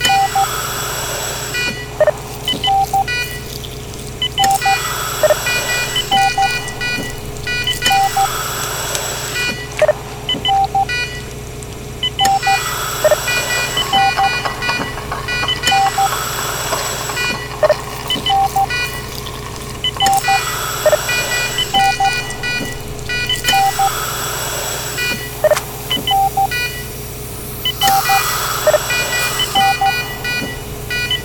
operatingroom.ogg